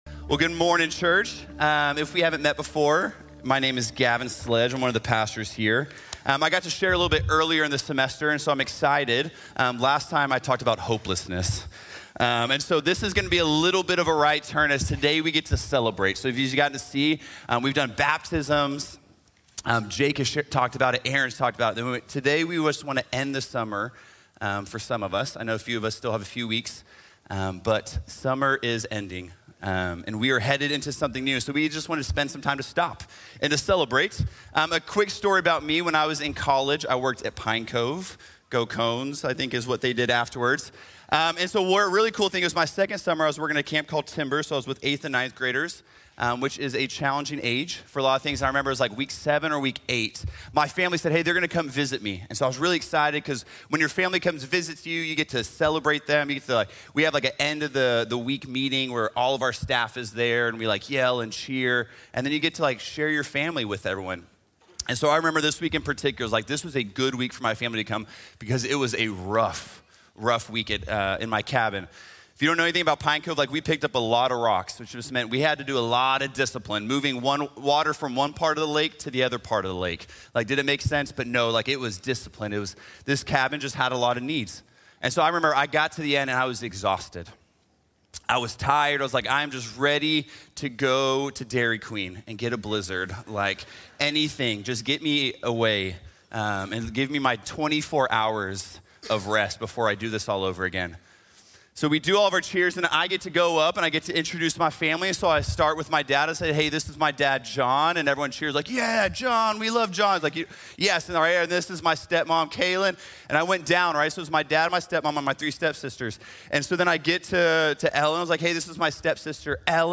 Remember and Celebrate | Sermon | Grace Bible Church